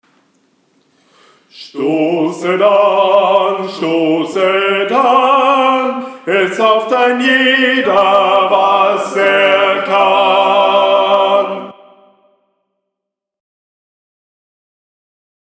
Tenor 3
36 Stosset an TENOR 3.mp3